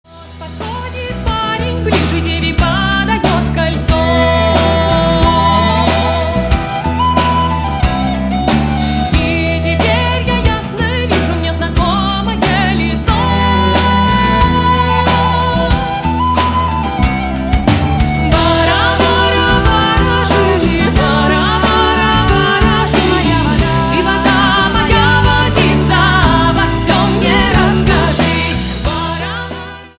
• Качество: 128, Stereo
красивый женский голос
фолк